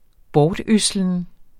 Udtale [ -ˌøslən ]